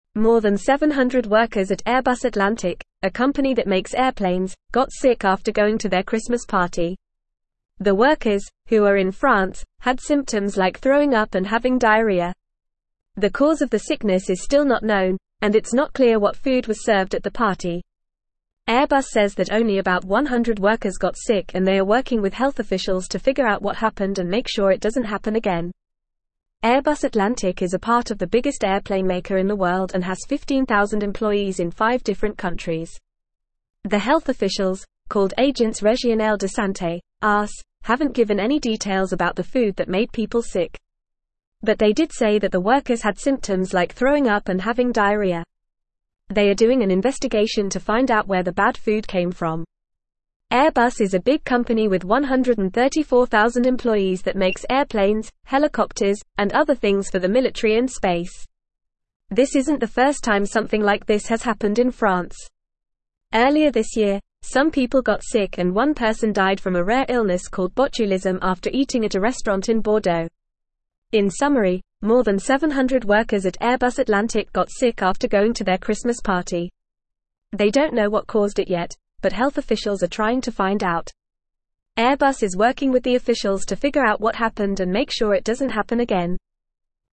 Fast
English-Newsroom-Upper-Intermediate-FAST-Reading-Over-700-Airbus-staff-fall-ill-after-Christmas-dinner.mp3